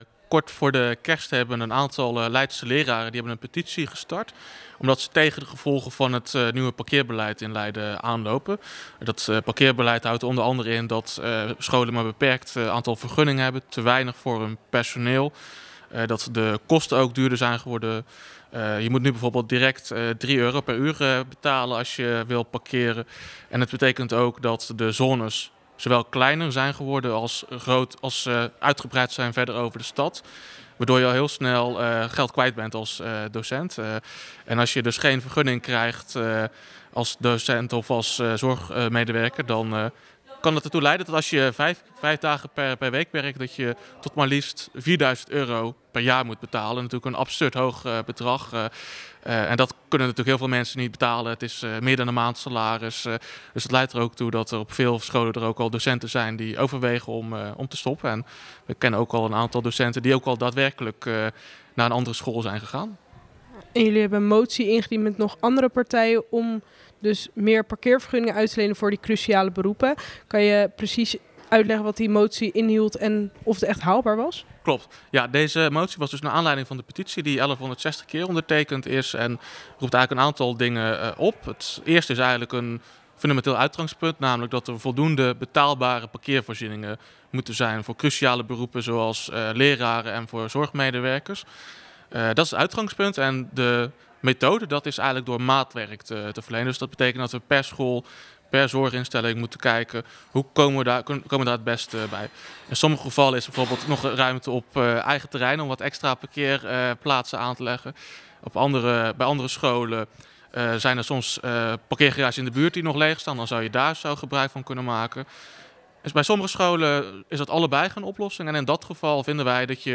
gaat in gesprek met fractievoorzitter van Partij Sleutelstad